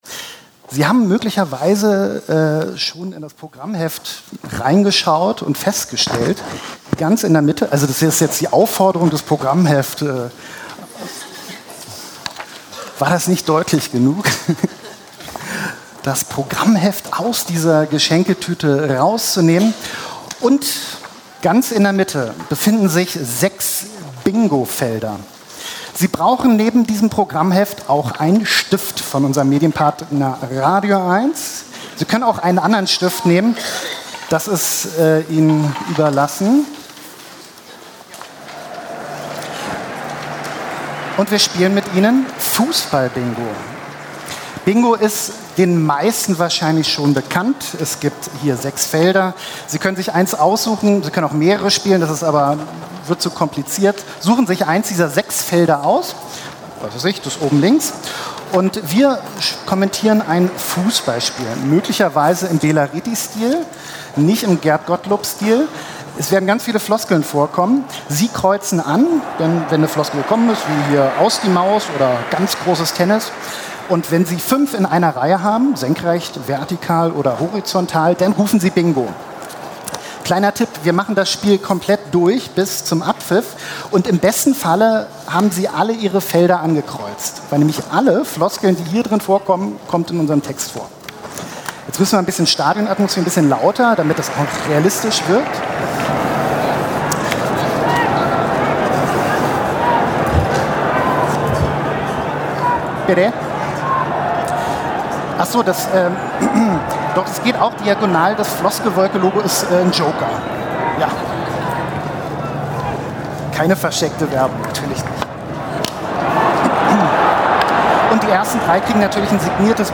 Potsdam, Museum Barberini